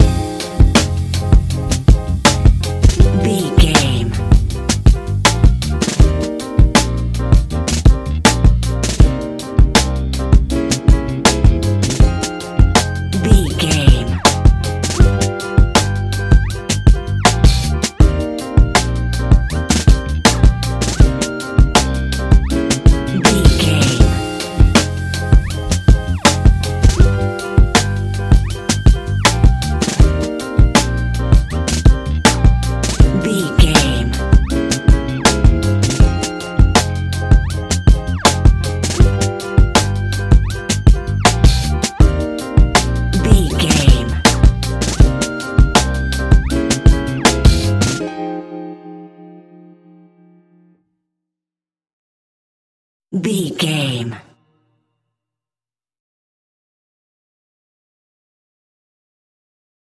Ionian/Major
F♯
chilled
laid back
sparse
new age
chilled electronica
ambient
atmospheric